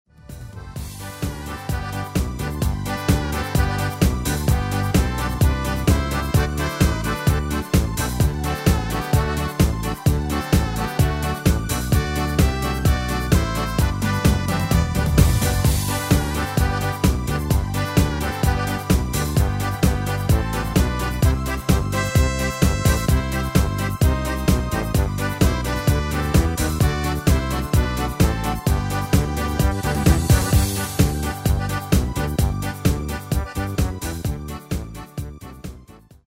Demo/Koop midifile
Genre: Nederlands amusement / volks
Toonsoort: G
Demo's zijn eigen opnames van onze digitale arrangementen.